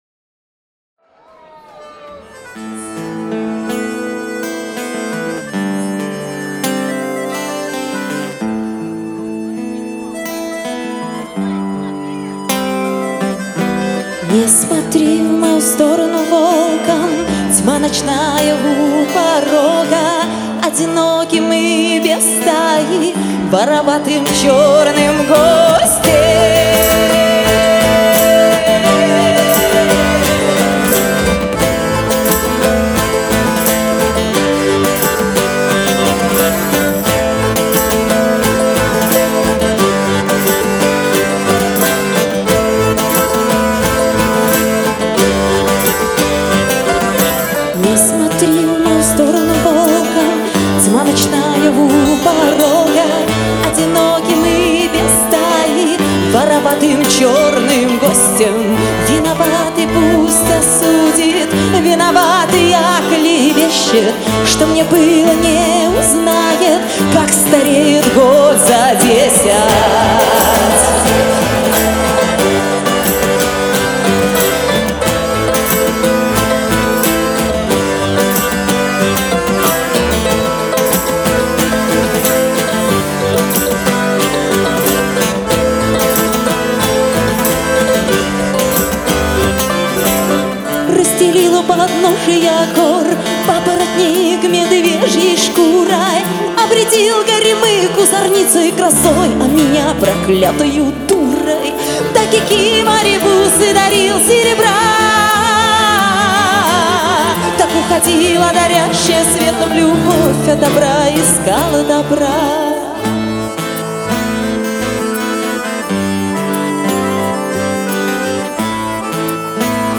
вокал
акустическая гитара
балалайка
перкуссия, ударные
аккордеон
Треки с акустического концертного альбома